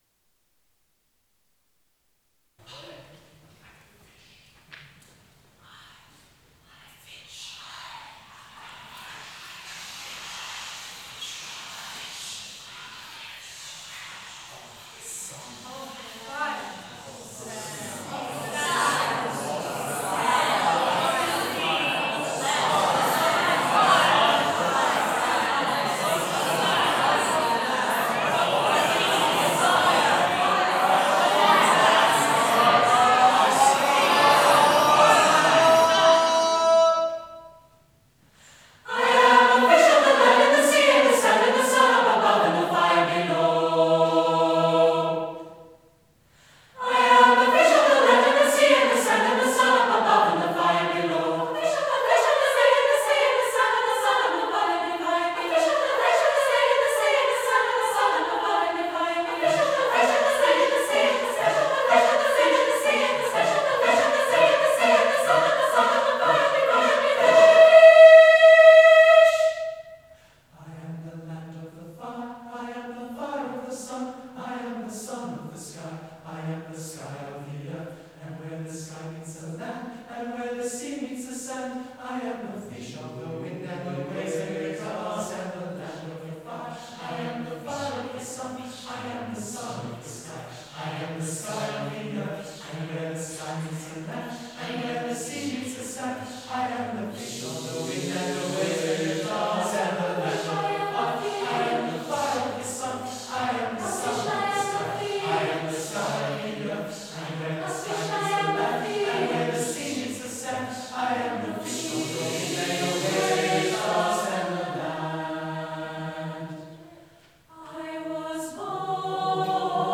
live recording